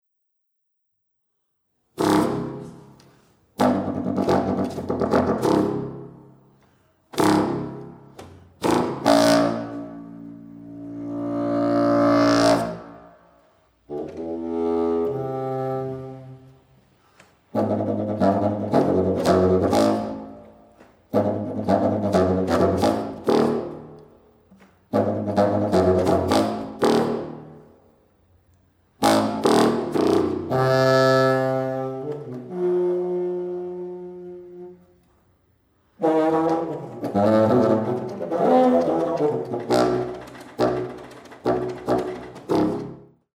Kontraforte
Juni 2010, Wien (UA)